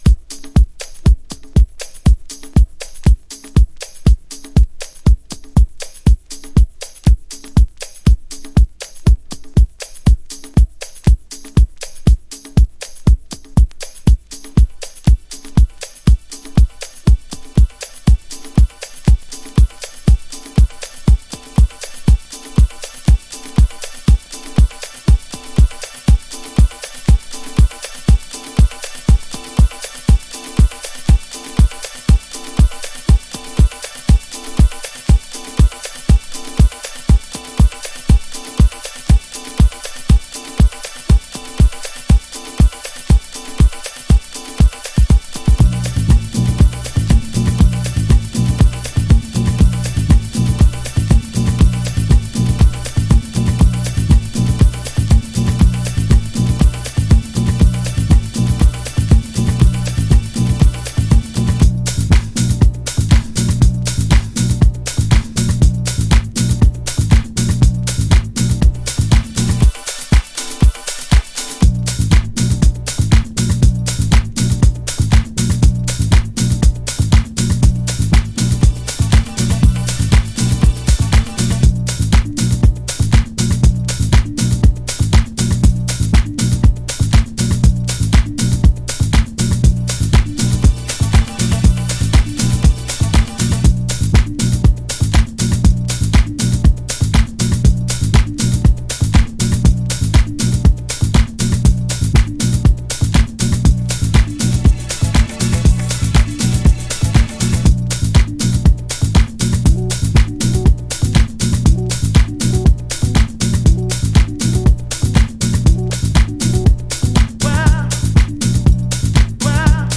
House / Techno
ディスコサンプルをタフなハウスグルーヴに落とし込んだデトロイトハウススタイル。